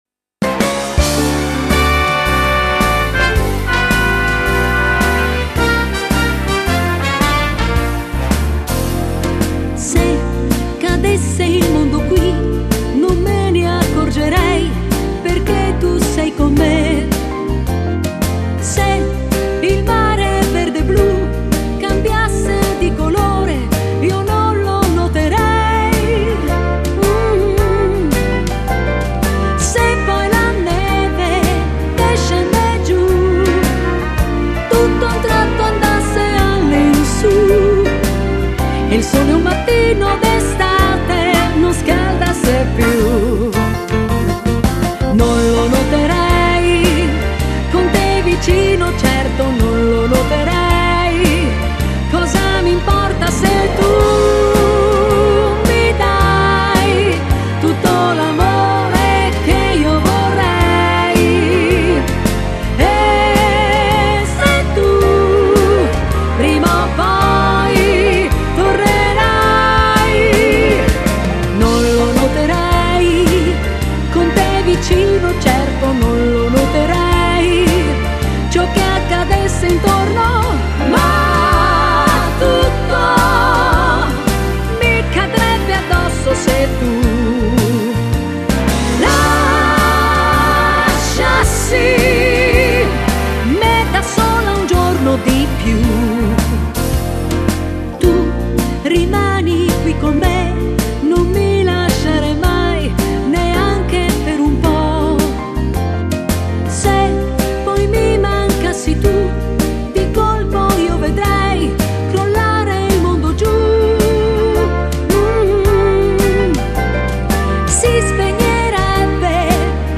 Genere: Swing moderato